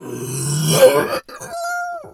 bear_pain_hurt_groan_04.wav